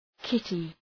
{‘kıtı}